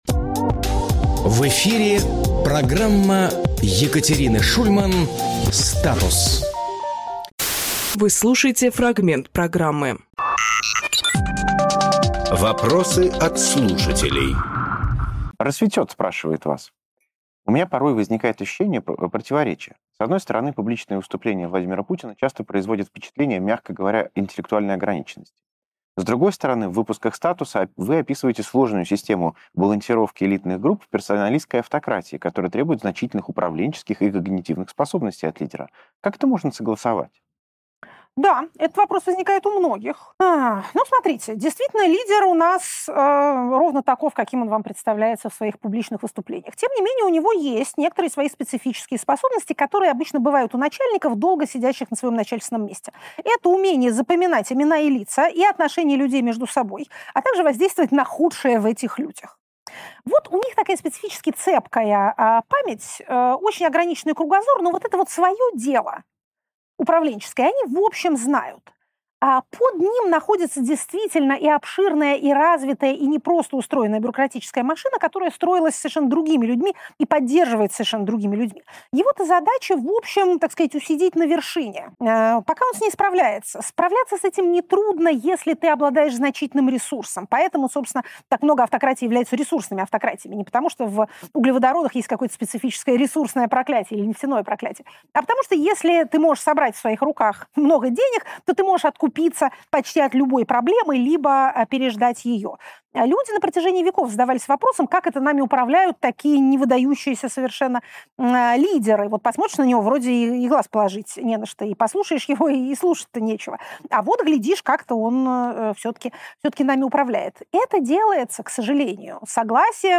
Екатерина Шульманполитолог
Фрагмент эфира от 31.03.26